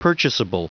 Prononciation du mot purchasable en anglais (fichier audio)
Prononciation du mot : purchasable